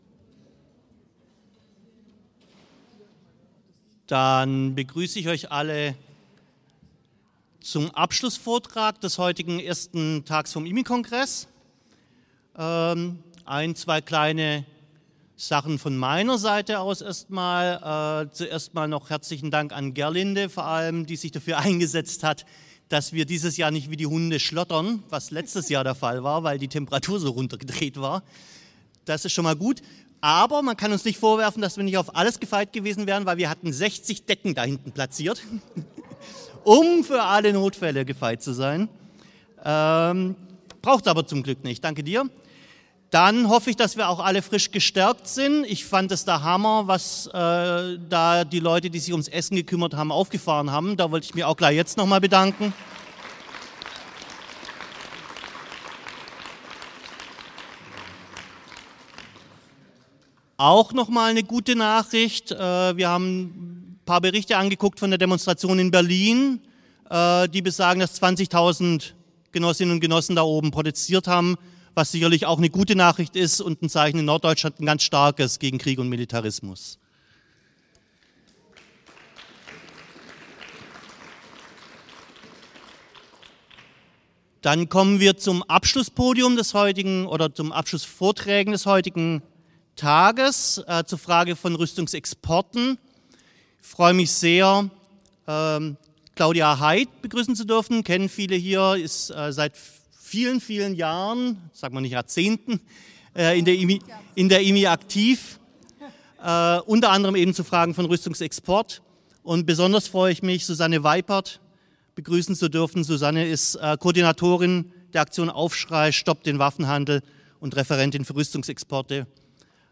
Anmoderation Panel: Rüstungsexporte Vehikel für Macht- und Interessenspolitik